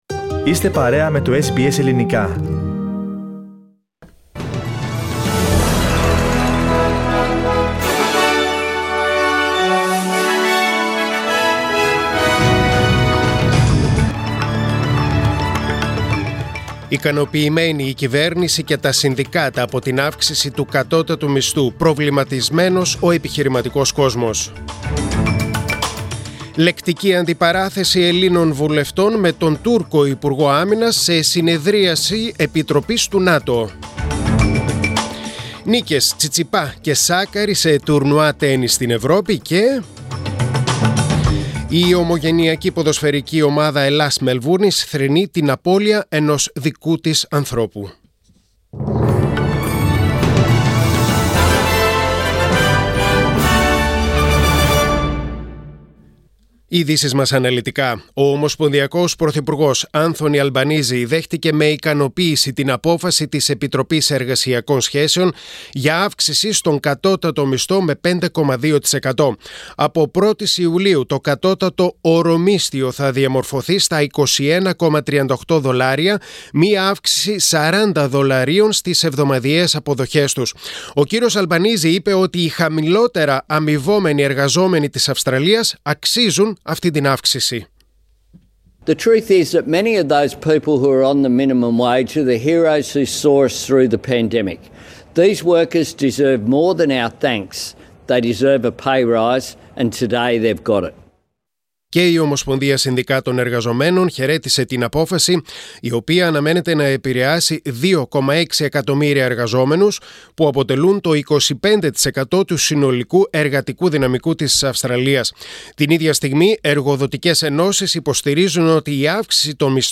Δελτίο Ειδήσεων: Τετάρτη 15.6.2022